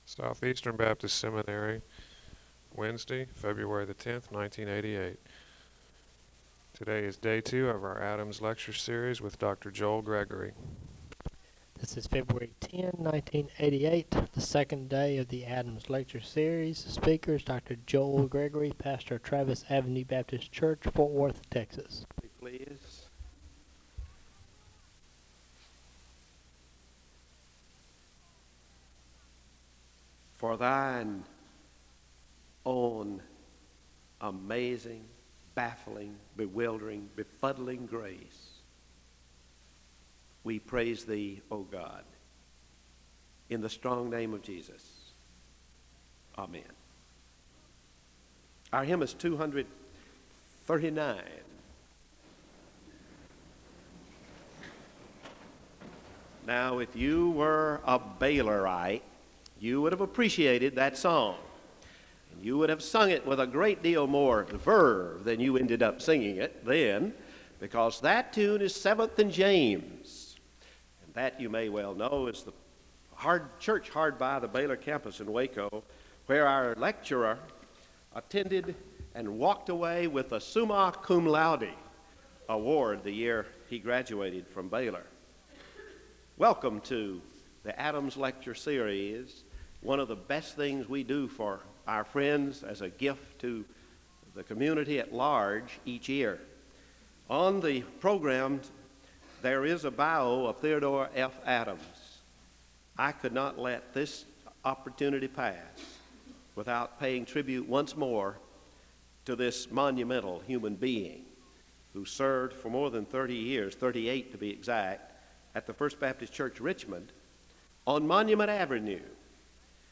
Home SEBTS Adams Lecture